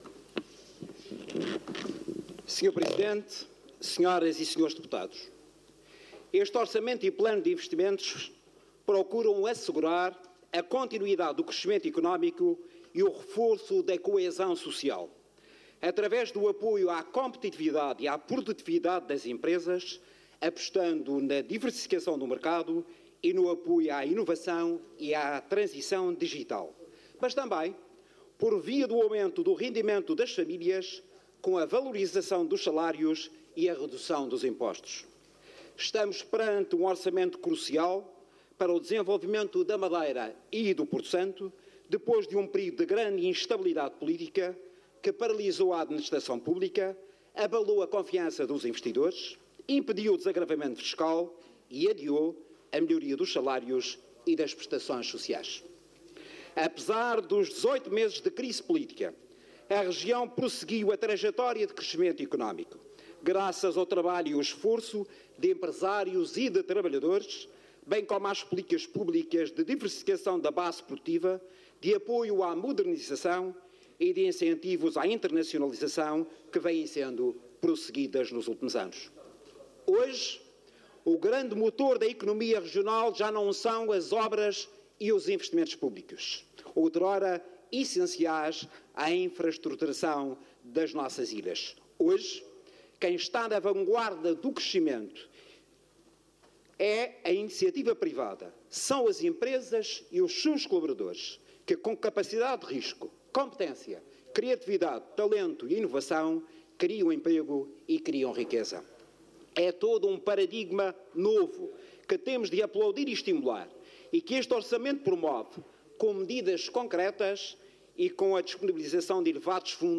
Intervenção SREC ORAM 2025_Som.mp3